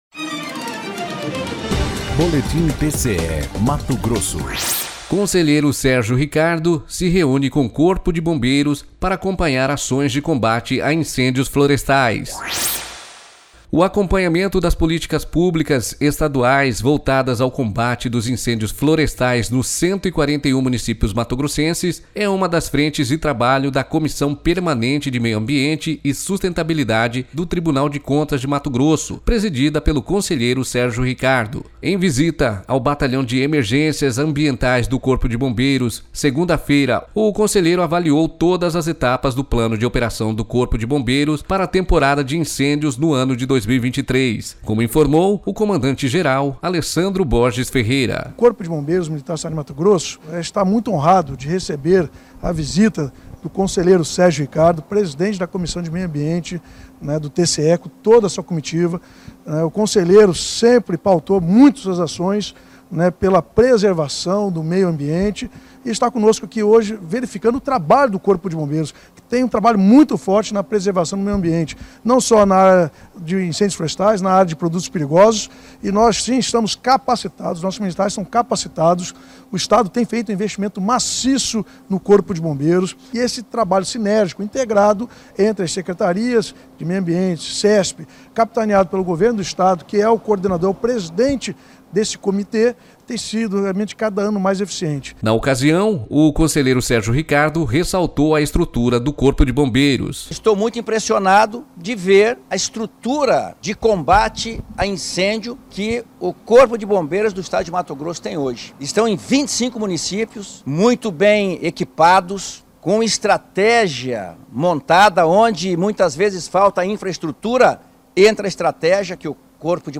Sonora: Alessandro Borges Ferreira - comandante-geral do Corpo de Bombeiros de MT
Sonora: Sérgio Ricardo – conselheiro e presidente da CPMAS do TCE-MT